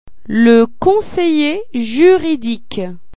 arrêter   chap kh*loo-uhn
La prison   kuk
Le prisonnier   nay-uhk toH